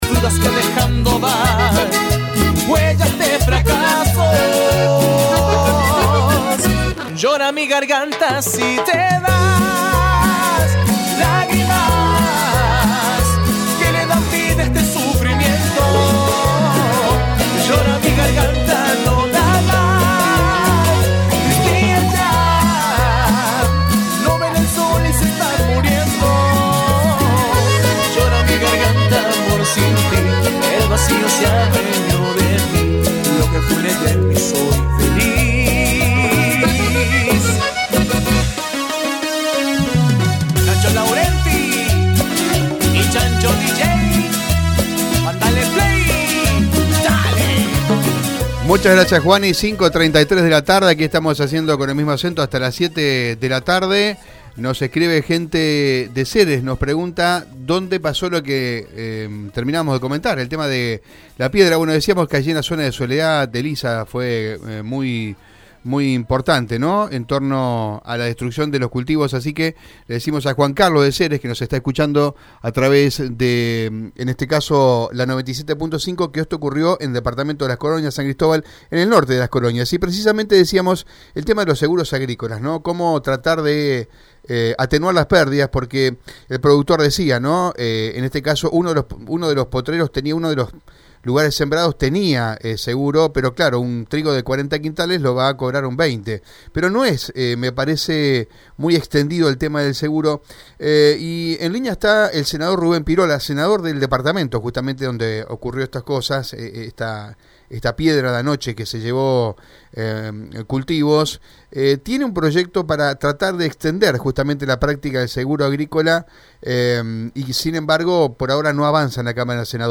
El Senador por el departamento Las Colonias, Rubén Pirola, dialogó con Radio EME sobre su proyecto para proveer de un seguro a los productores afectados por temporales.